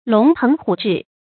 龍騰虎擲 注音： ㄌㄨㄙˊ ㄊㄥˊ ㄏㄨˇ ㄓㄧˋ 讀音讀法： 意思解釋： 同「龍騰虎蹴」。